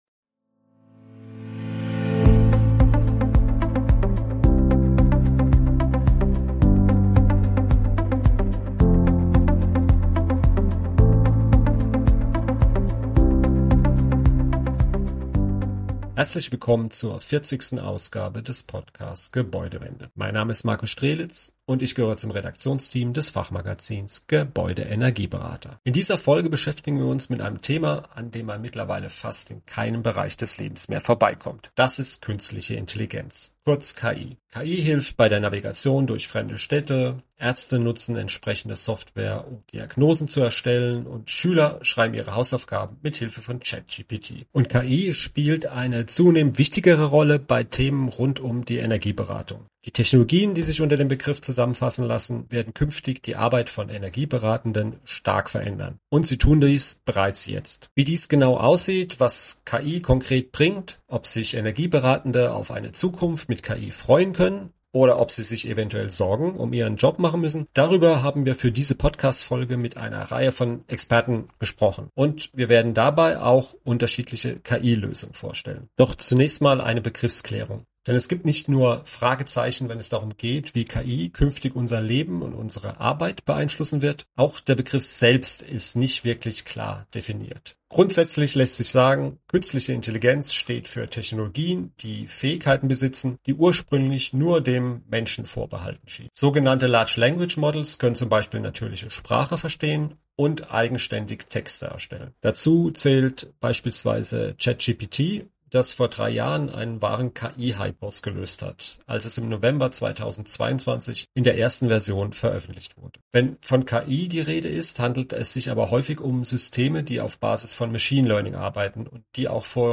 Im Podcast Gebäudewende erklären Expertinnen und Experten, an welchen Stellen KI-Systeme dem Menschen unter die Arme greifen können. Sie sprechen aber auch über die Grenzen der Technologien und über die Frage, ob Energieberatende künftig Angst um ihren Job haben müssen.